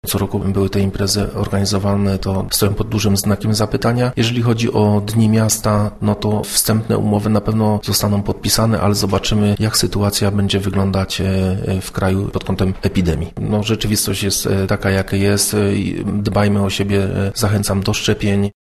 W Działoszynie nie odbędzie się zabawa sylwestrowa pod gołym niebem. O takiej ewentualności już wcześniej mówił na naszej antenie burmistrz